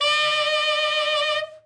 Index of /90_sSampleCDs/Giga Samples Collection/Sax/SAXOVERBLOWN
TENOR OB  21.wav